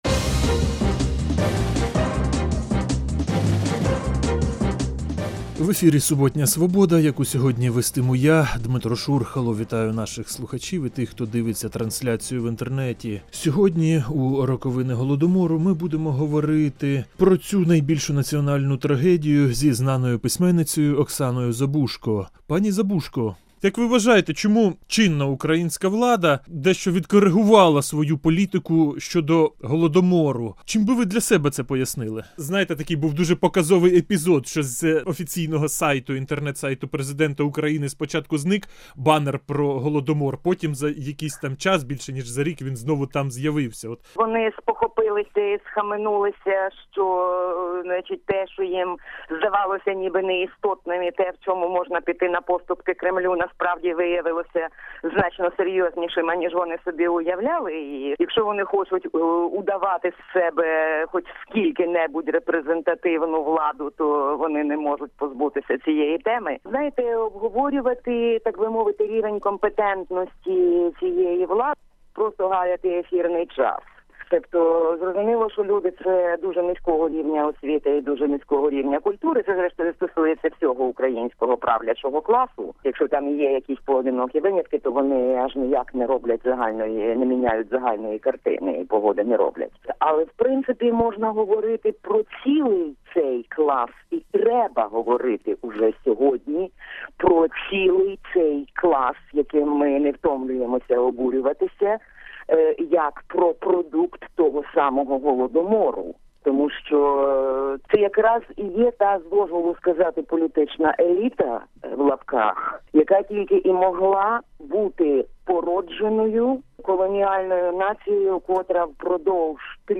Суботнє інтерв'ю